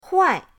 huai4.mp3